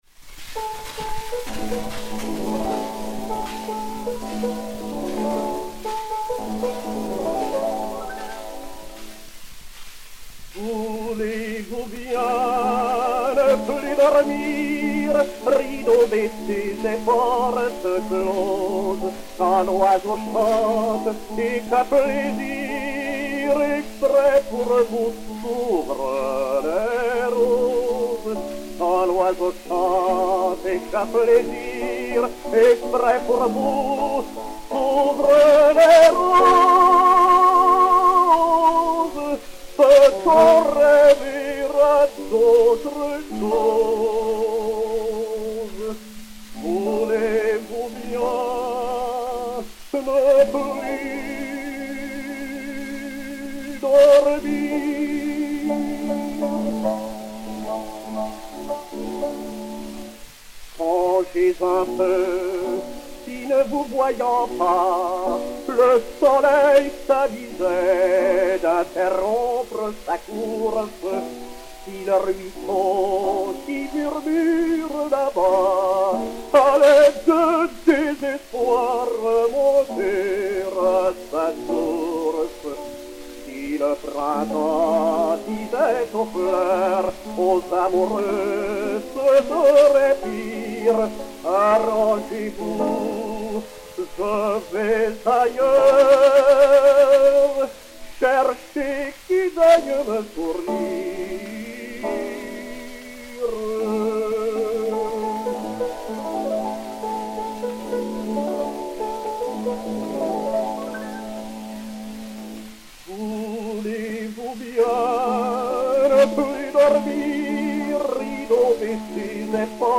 Piano
Disque Pour Gramophone 2-32522, mat. 859F, enr. à Paris en 1902